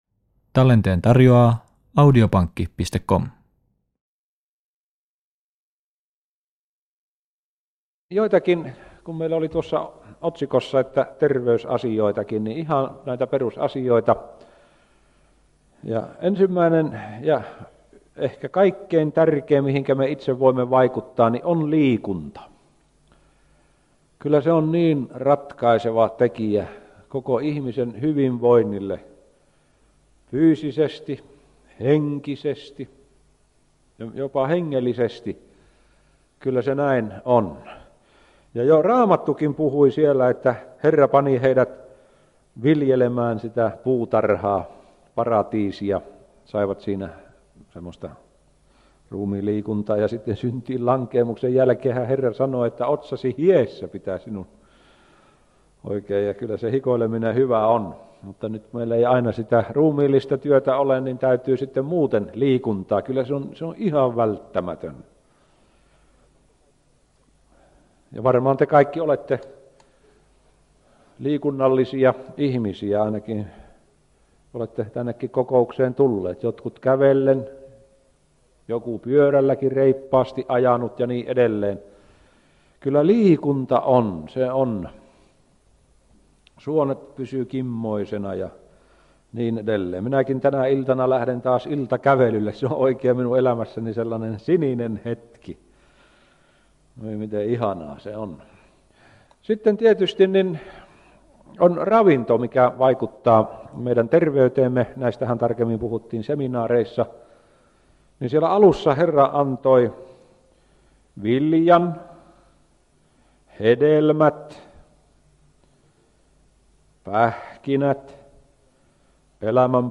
Tämä on 17 -osainen puhesarja, jossa tutustutaan Raamatun tärkeimpiin opetuksiin.